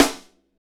Index of /90_sSampleCDs/Northstar - Drumscapes Roland/SNR_Snares 1/SNR_Funk Snaresx